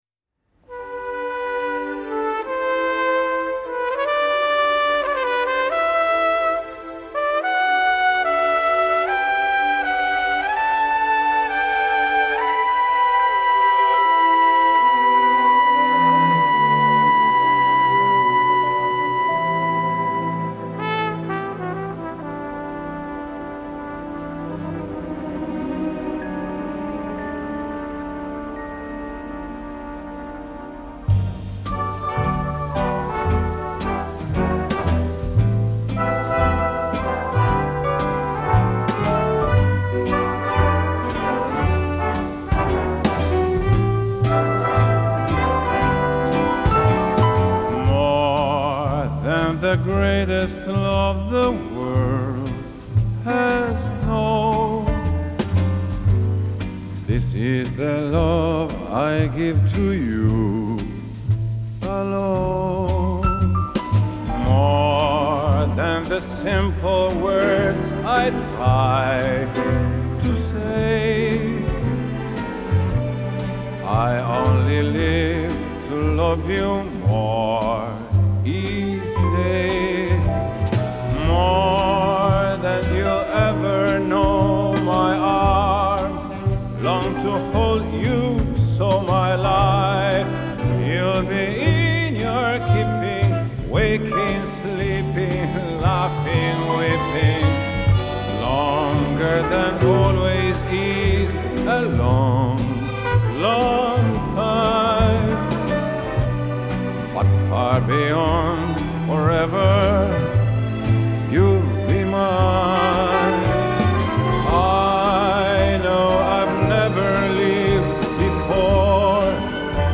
Genre : Classical
Live At Central Park, New York